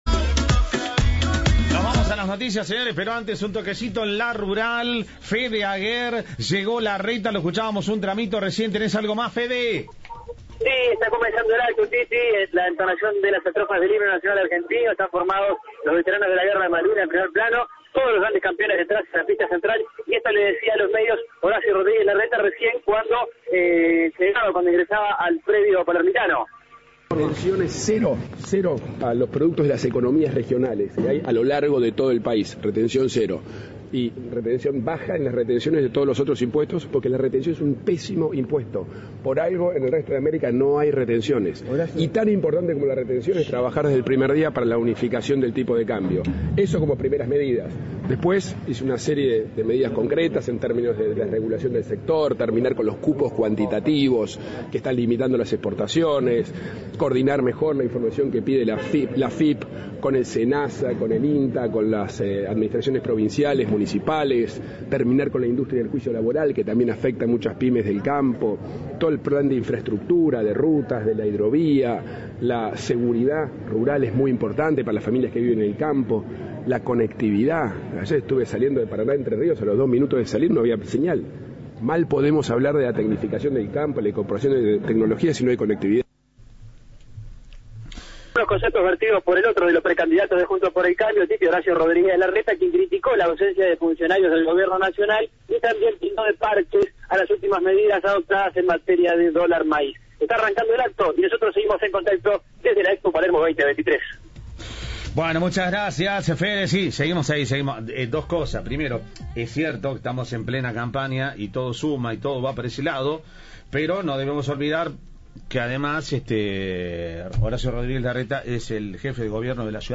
Audio. Larreta, desde La Rural: "Incorporaremos tecnología y conectividad al campo"